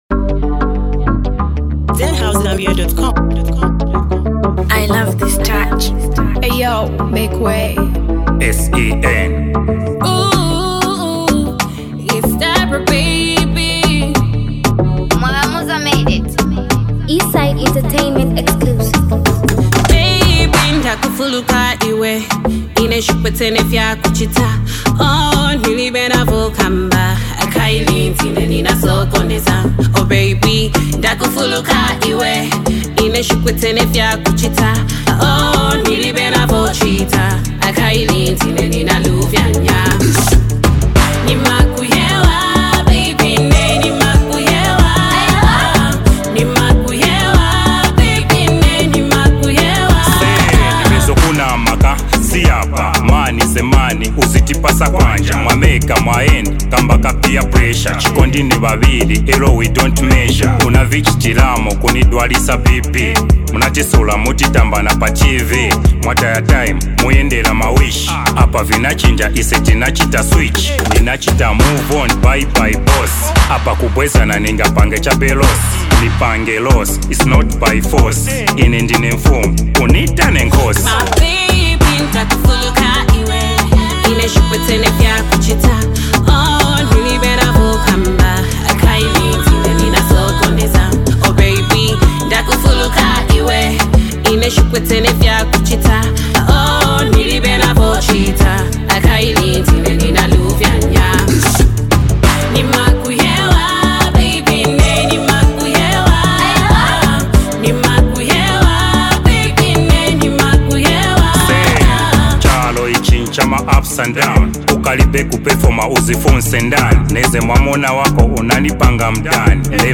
a heartfelt love tune